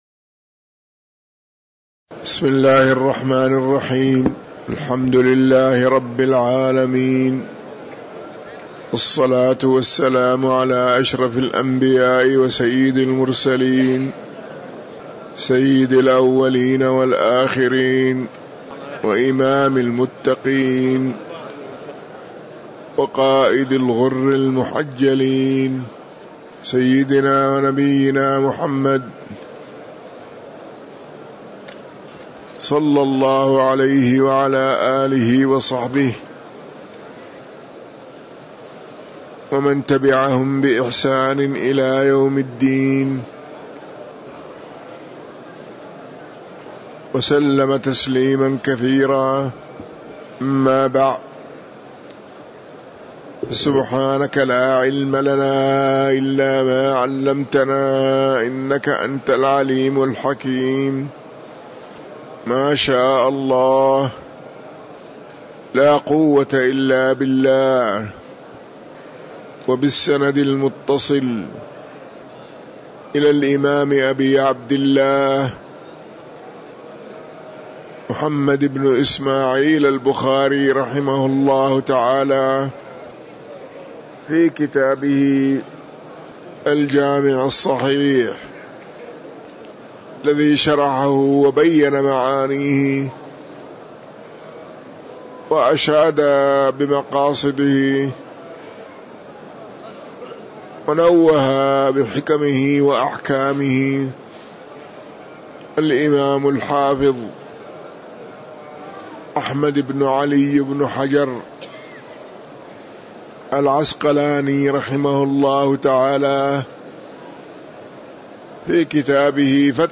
تاريخ النشر ٥ رمضان ١٤٤٣ هـ المكان: المسجد النبوي الشيخ